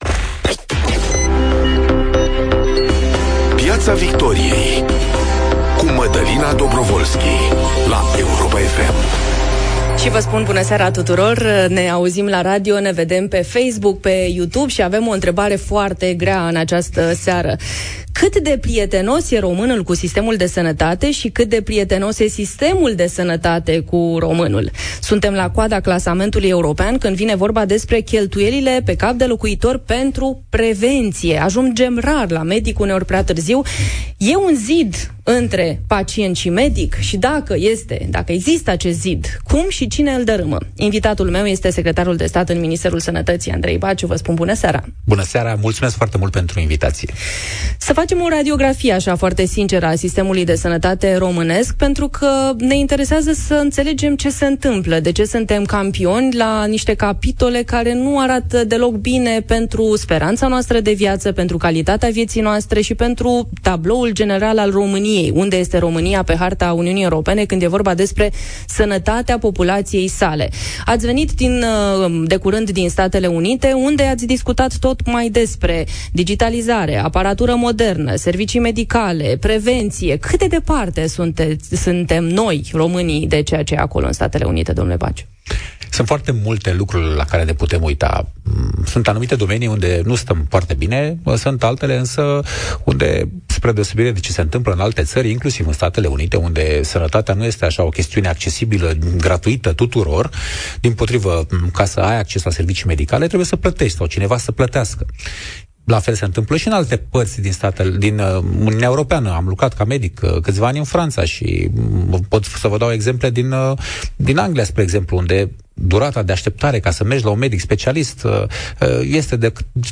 Cât de prietenos e românul cu sistemul de sănătate? Invitat este Andrei Baciu, secretar de stat în Ministerul Sănătății - 08.05.2023